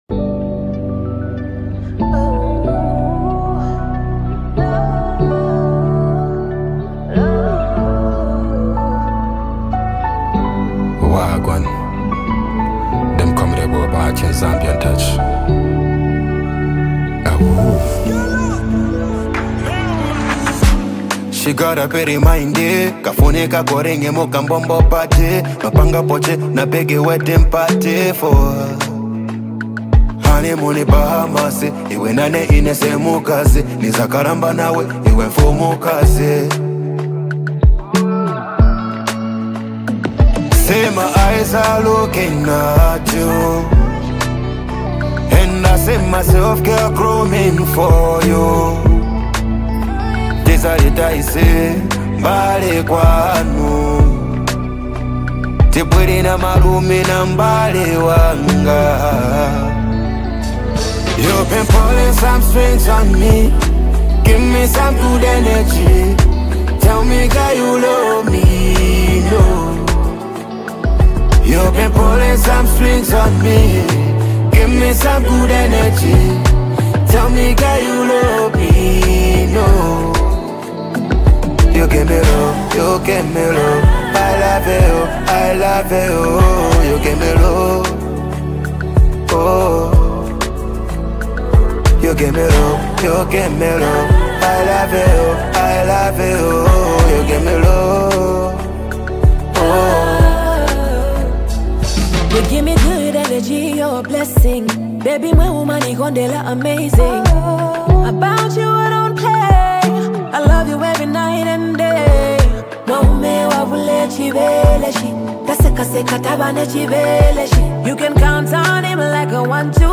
velvety vocals
lush harmonies